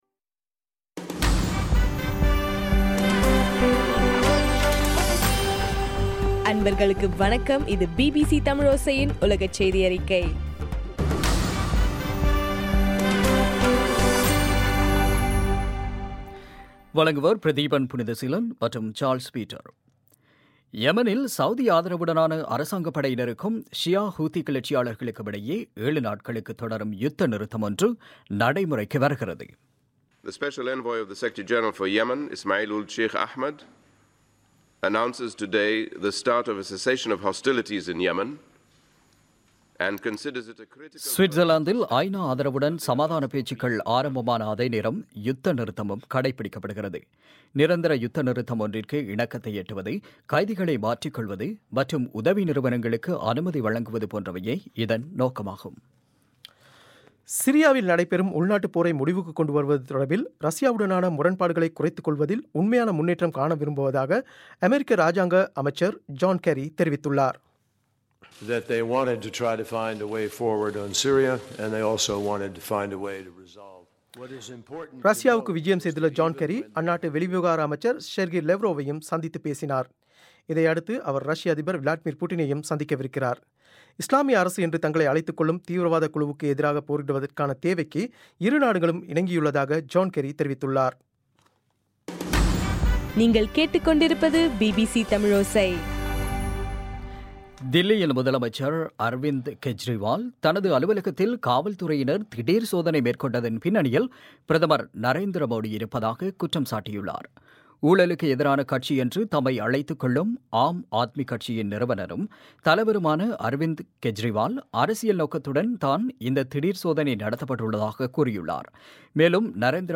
டிசம்பர் 15, 2015 பிபிசி தமிழோசையின் உலகச் செய்திகள்